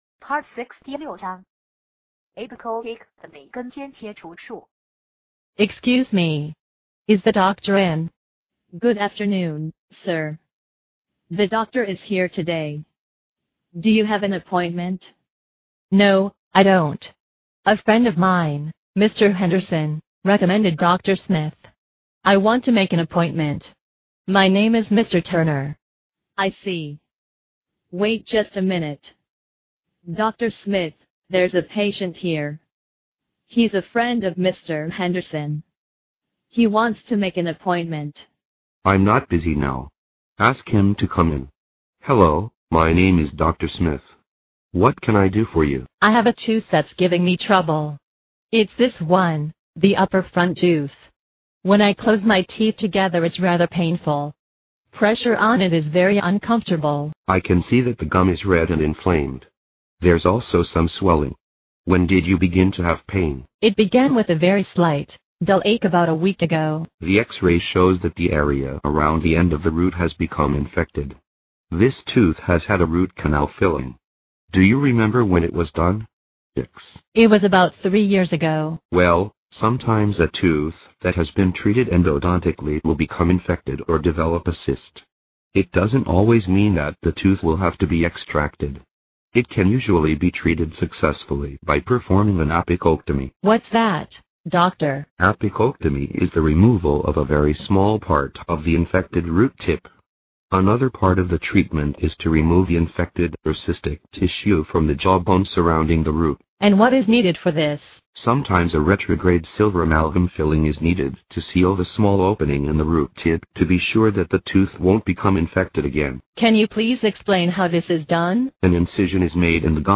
收录了口腔医学医患、医助之间的对话，非常适合医学生、临床医务人员练习专业口语和听力，在欧洲很受欢迎。